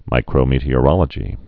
(mīkrō-mētē-ə-rŏlə-jē)